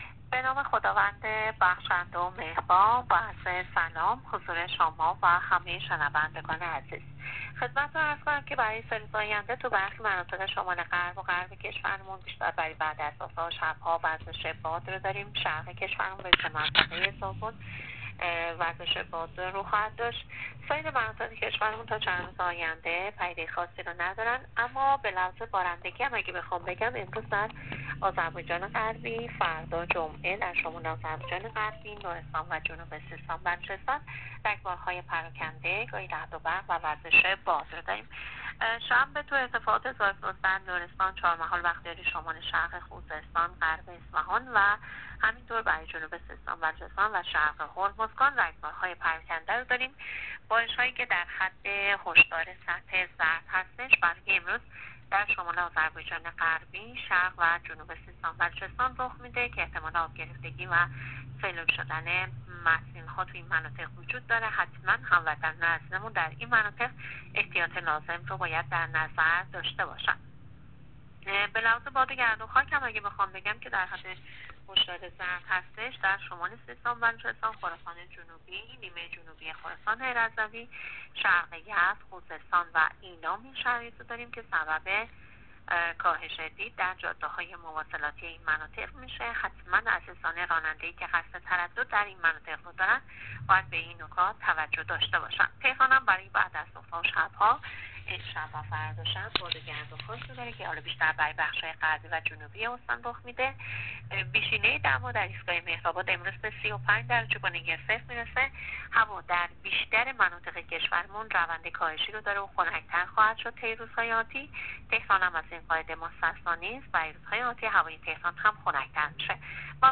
گزارش رادیو اینترنتی پایگاه خبری از آخرین وضعیت آب‌وهوای بیست و هفتم مردادماه؛